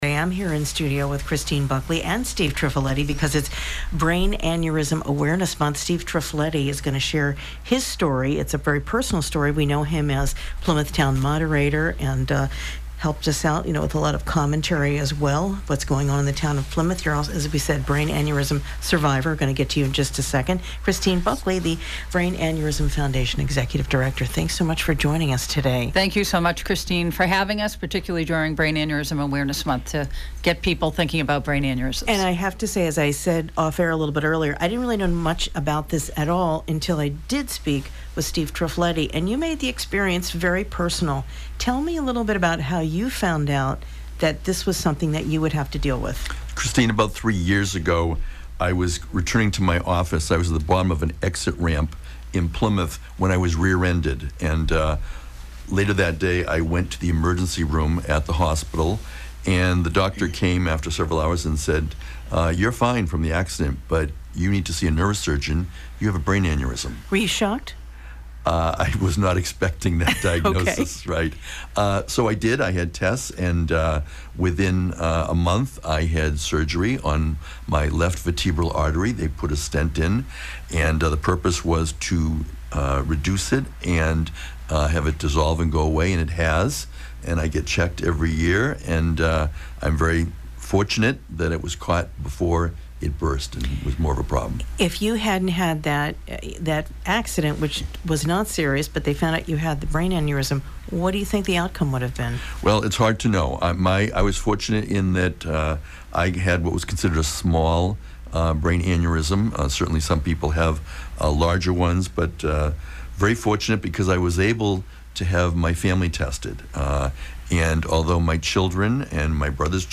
Was Live In-Studio